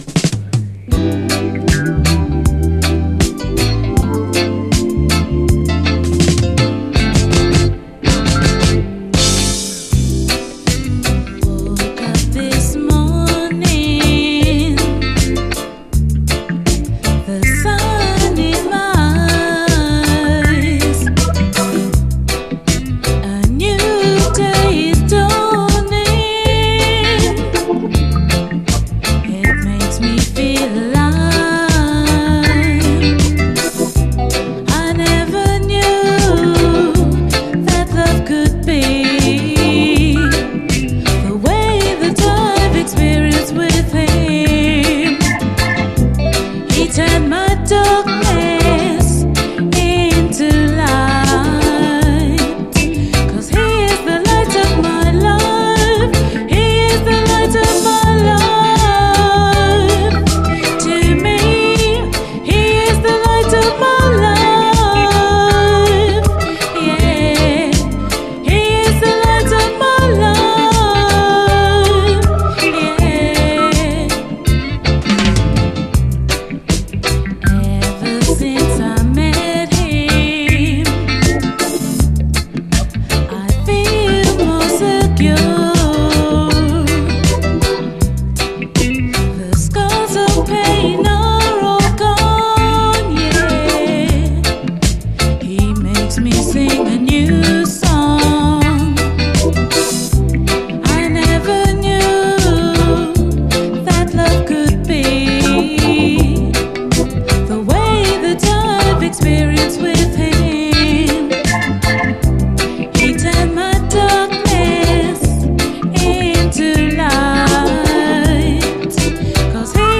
REGGAE
切なさが胸に迫る最高レアUKラヴァーズ！